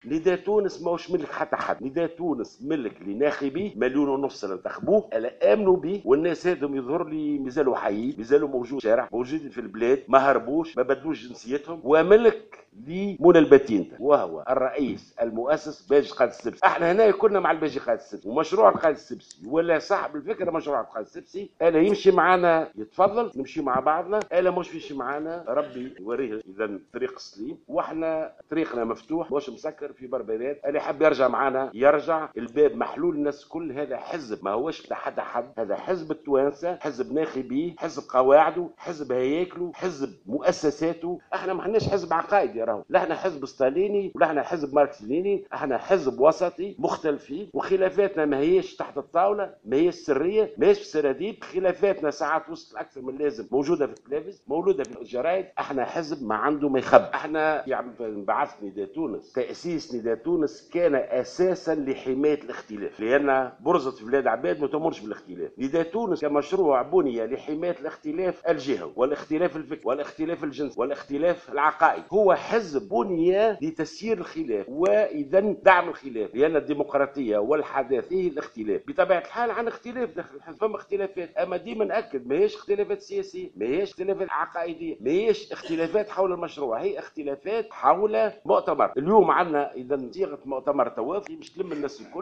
واعتبر في ندوة صحفية عقدها على هامش اجتماع للمكتب التنفيذي لنداء تونس اليوم في ضاحية قمرت بالعاصمة تونس، أن هذا الحزب هو مشروع سياسي تأسس على مشروع الحداثة التي تعود لعهد حمود باشا منذ 1811 ويسعى إلى إنقاذ المشروع البورقيبي.